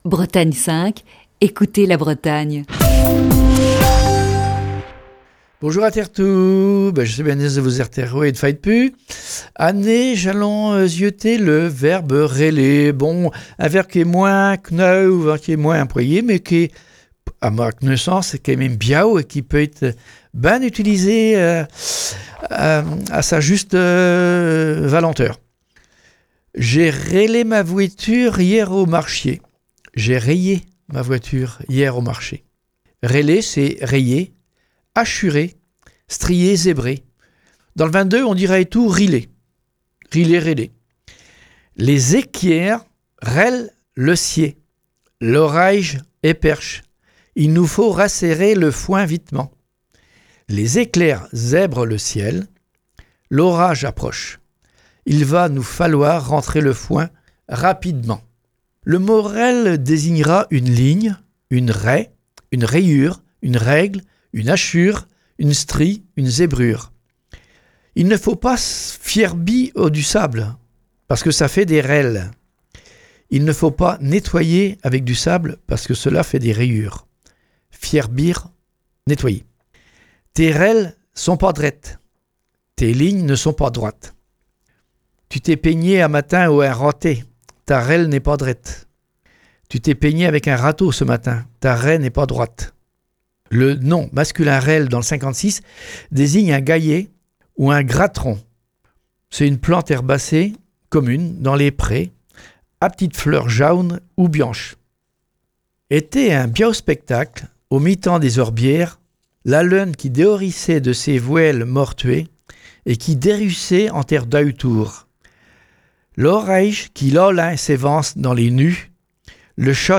Chronique du 26 août 2020.